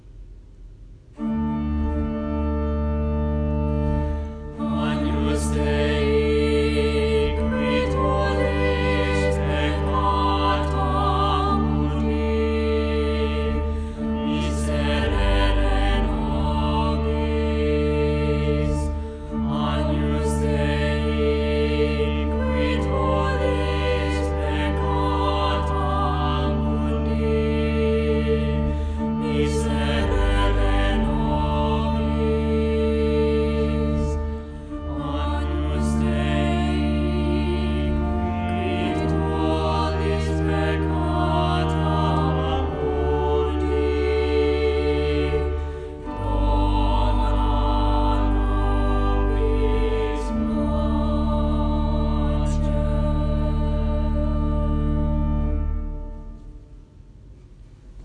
Schola 4-part (David Mass recordings)
choir-agnus-dei.m4a